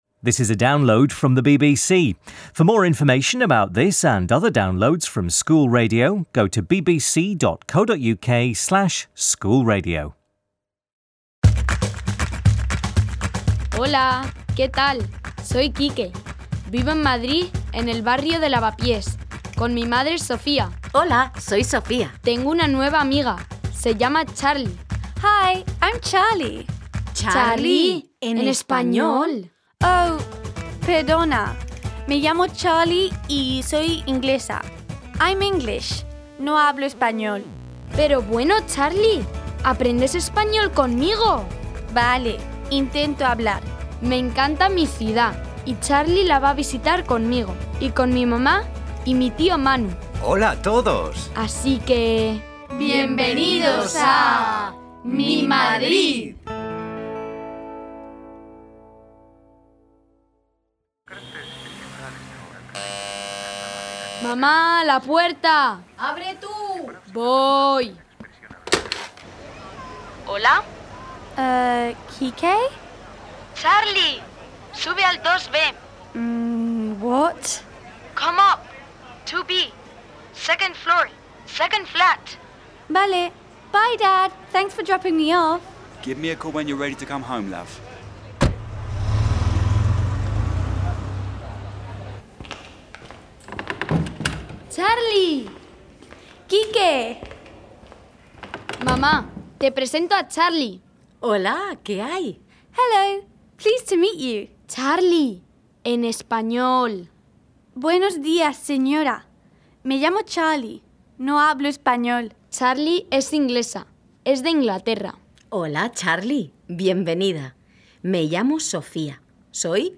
Charlie visits Quique's apartment for the first time and meets his mum, Sofía, his Uncle Manu and their neighbour Teresa. Teresa tells a story about getting to know your neighbours and Uncle Manu sings about their district of Madrid - Lavapiés. Key vocabulary includes greetings, names and animals and there is help with pronunciation of vowel sounds and comparing names in Spanish and English.